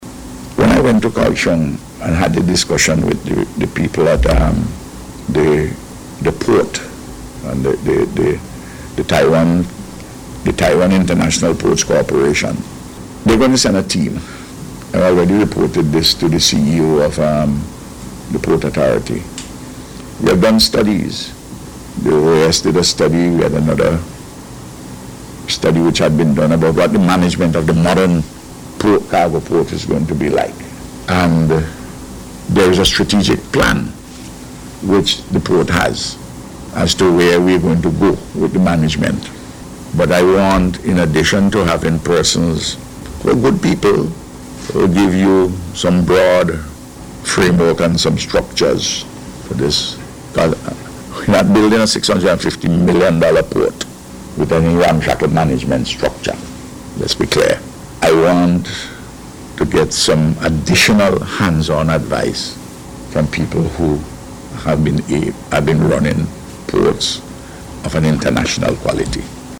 Word of this came from Prime Minister Dr. Ralph Gonsalves, during this morning’s News Conference.